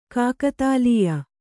♪ kākatālīya